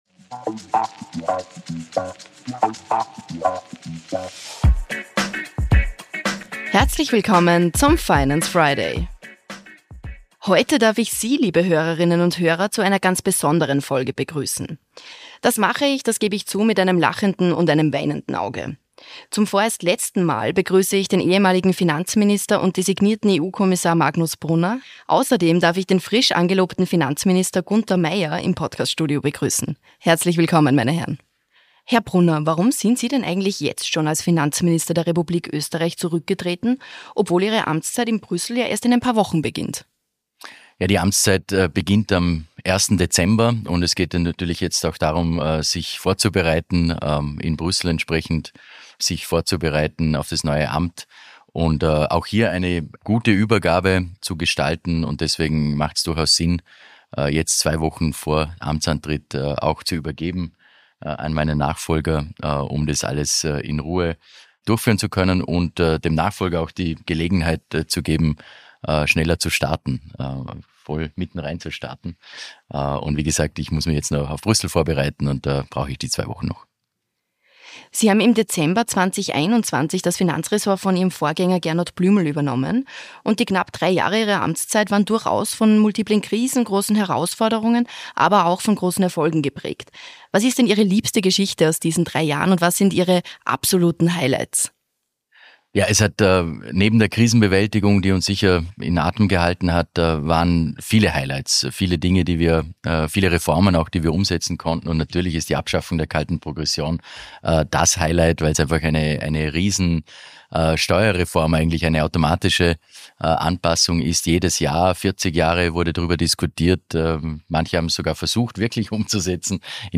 In der aktuellen Folge des „Finance Friday” unterhalten sich der designierte EU-Kommissar und ehemalige Finanzminister, Magnus Brunner und der neue Finanzminister, Gunter Mayr, miteinander – von Finanzminister zu Finanzminister sozusagen.